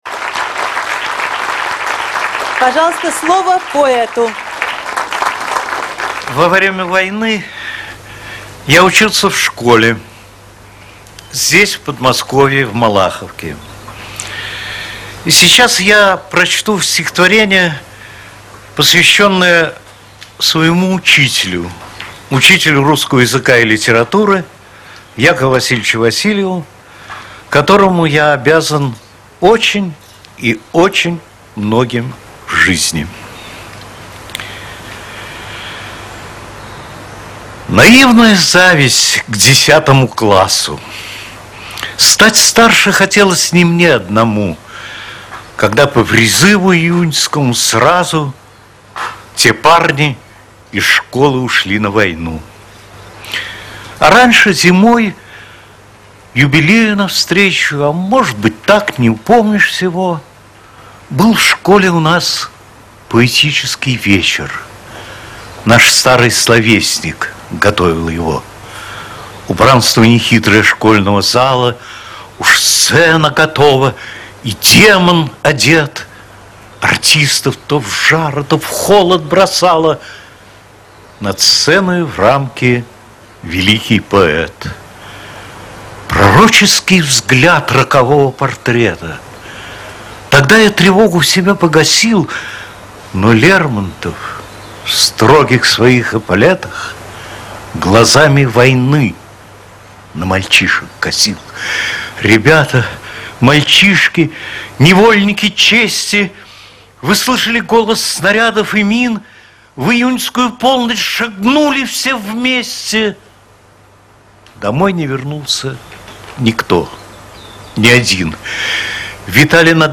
2. «Николай Добронравов – Наивная зависть к десятому классу (8.05.2012 исполняет автор)» /